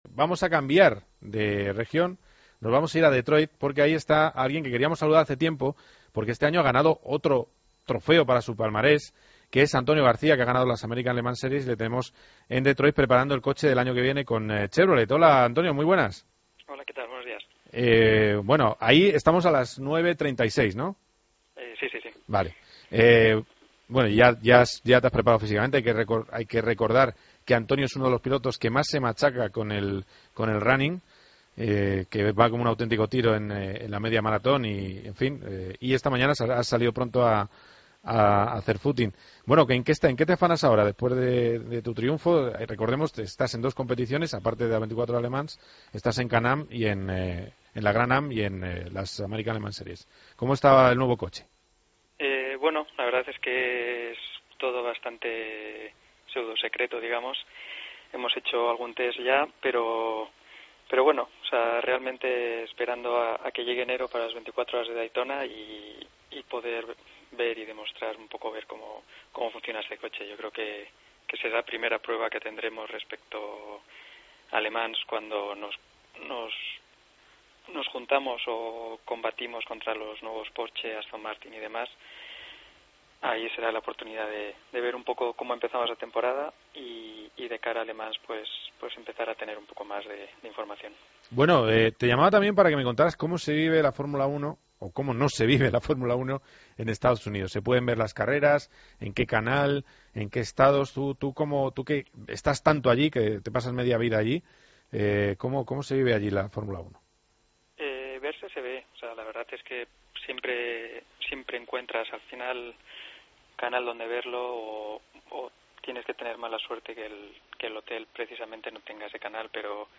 El piloto español habla en COPE GP, tras haber ganado las Américas Le Mans Series.